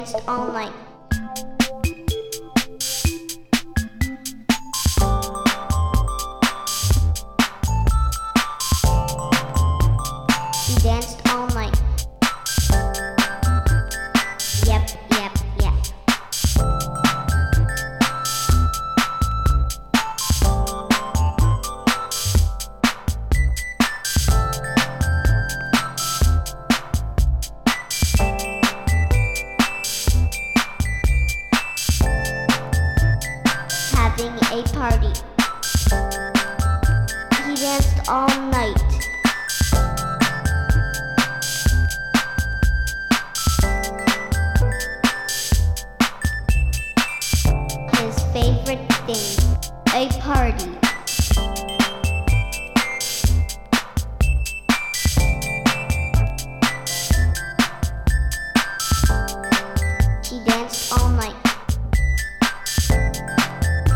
キュートな声ネタと、エモいエレクトロ・サウンドがマッチした